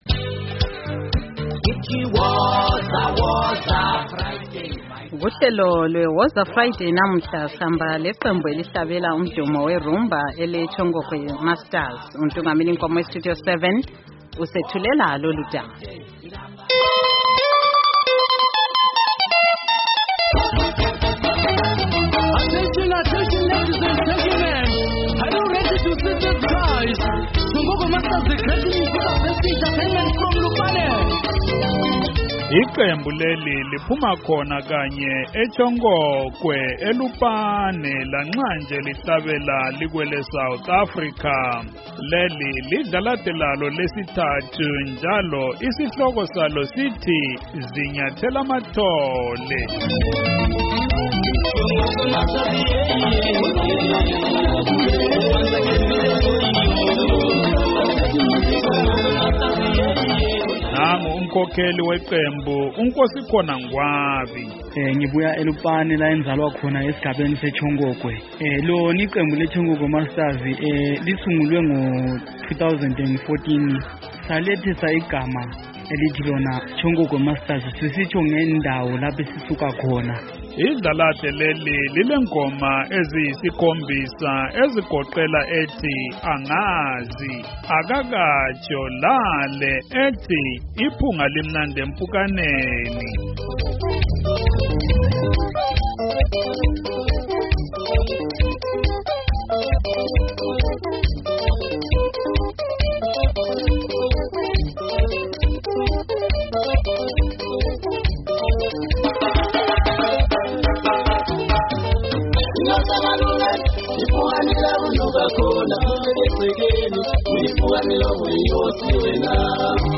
Kuhlelo lokuzilibazisa olweWoza Friday liviki sihamba leqembu elicula iRhumba eleTshongokwe Masterz.